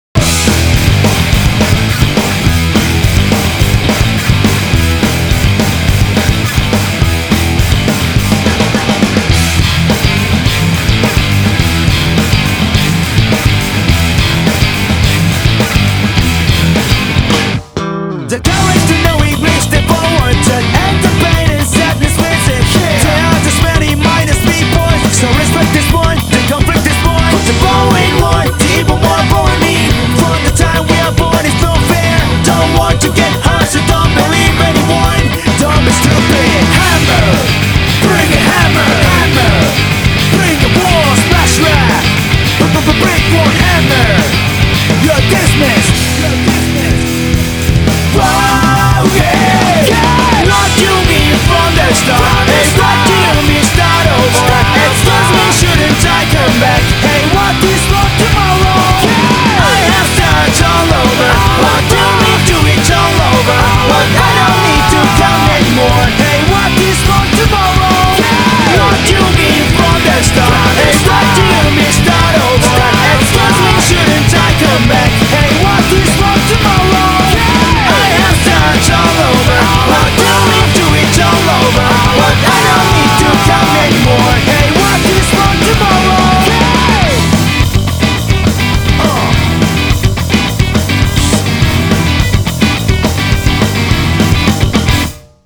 BPM105-210
Popular J-Rock